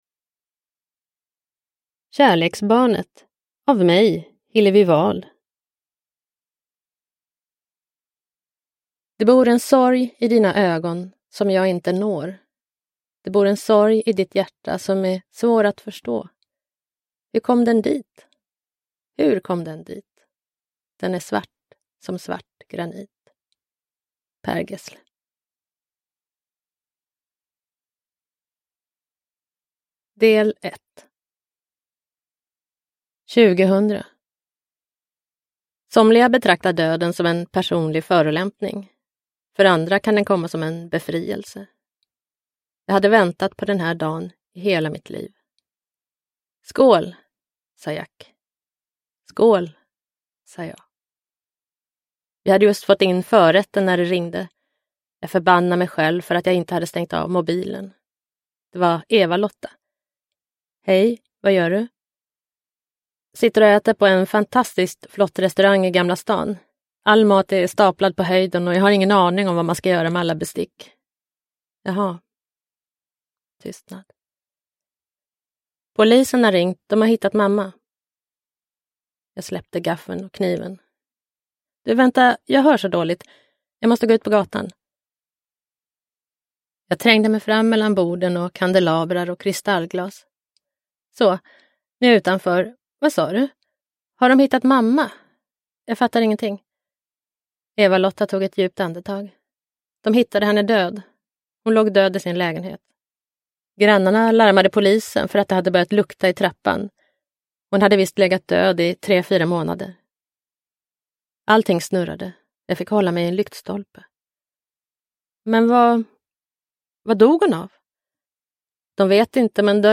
Kärleksbarnet : en berättelse om alkohol, bingo och brutna löften – Ljudbok – Laddas ner